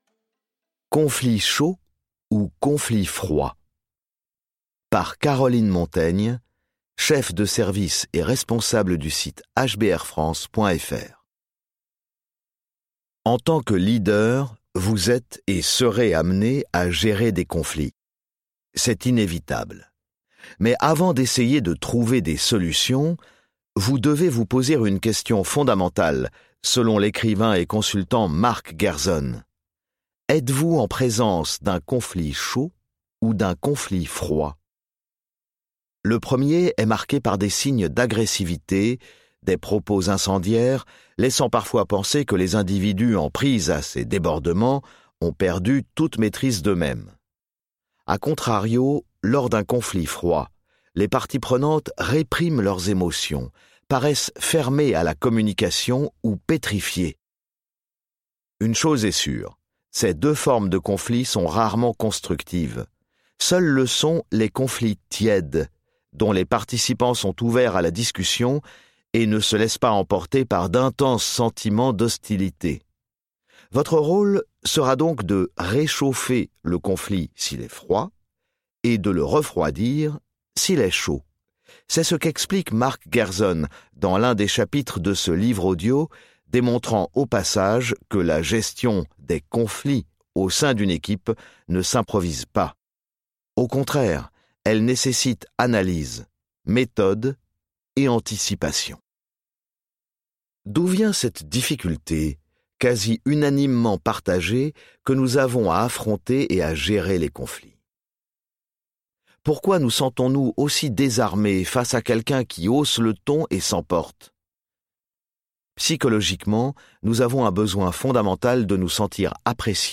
Extrait gratuit - Gérer les personnalités difficiles de Harvard Business Review